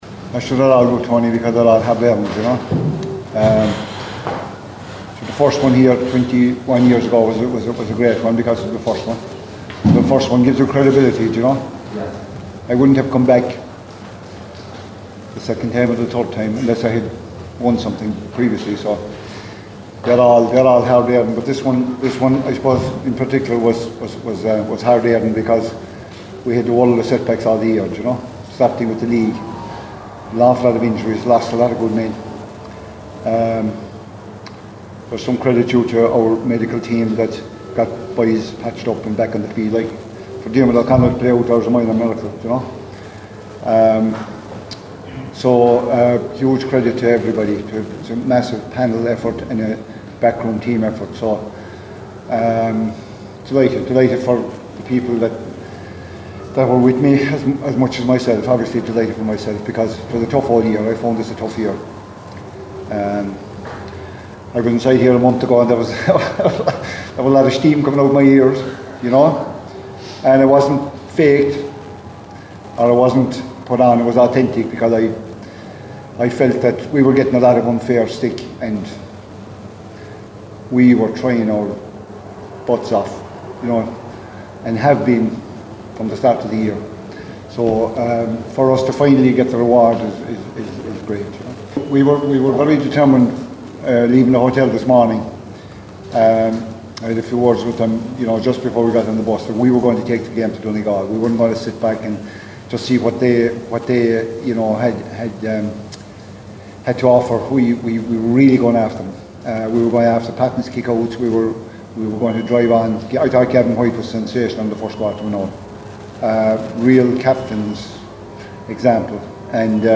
After the game, Kerry boss Jack O’Connor said getting off to a flying start was part of the plan…